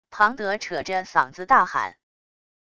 庞德扯着嗓子大喊wav音频